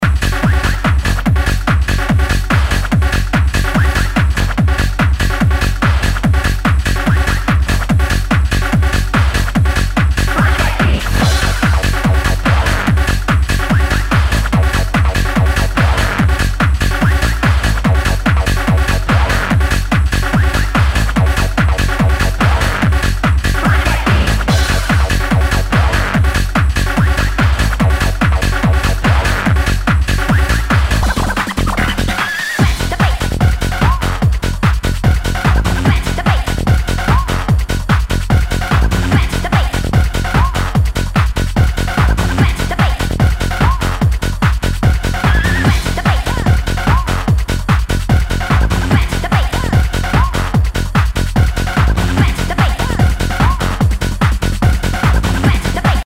HOUSE/TECHNO/ELECTRO
ハード・ハウス / トランス！